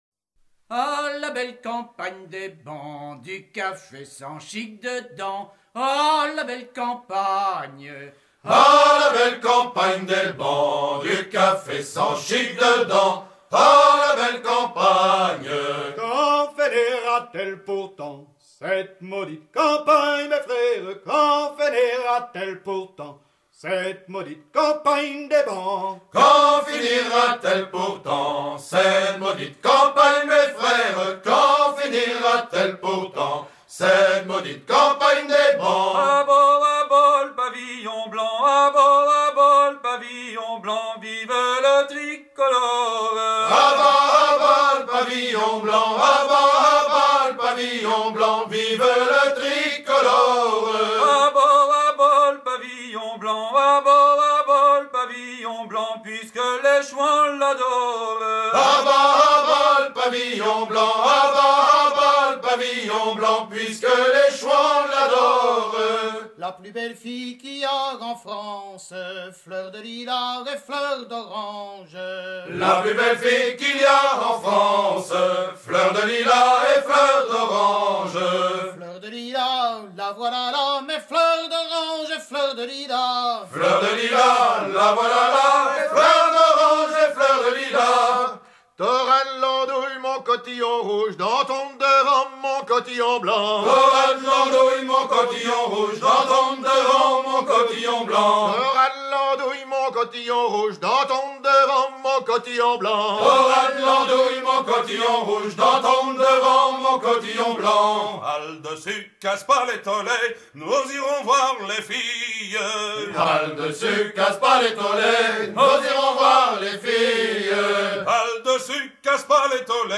Suite de chants à hisser main sur main
Suite de quatrains à hisser main sur main recueillis à Fécamp, Granville, Cancale, Saint-Malo
Pièce musicale éditée